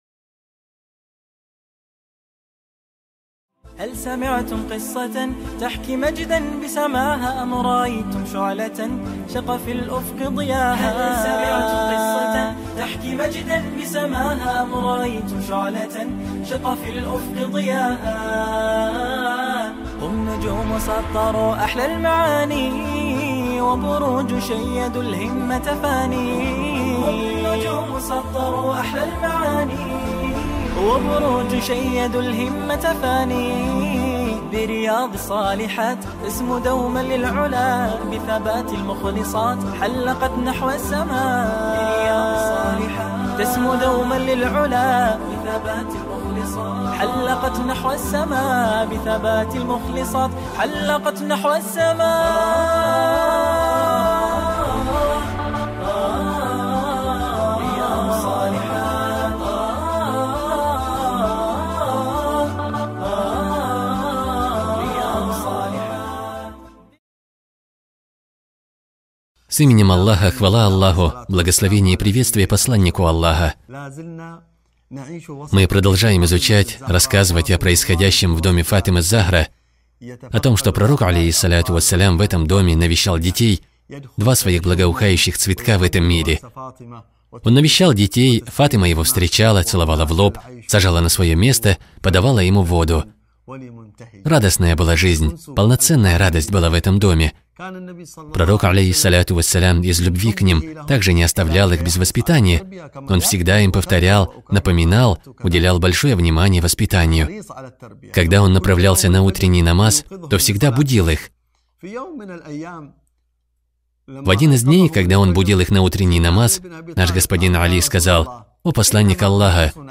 Совершенные женщины | Цикл уроков для мусульманок